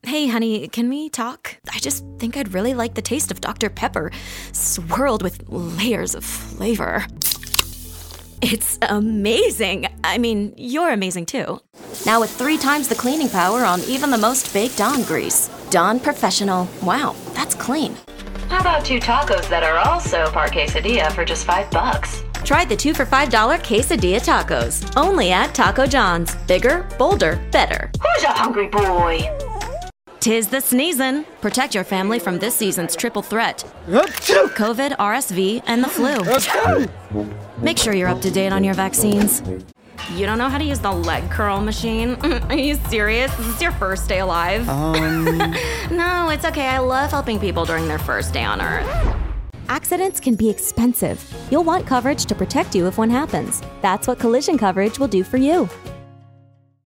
Voiceover : Commercial : Women
Commercial Demo